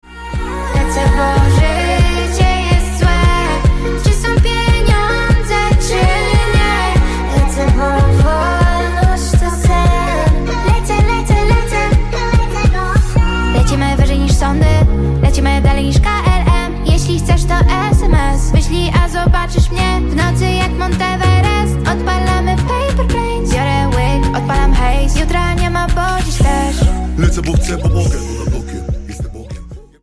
ringtone
Hip-Hop/Rap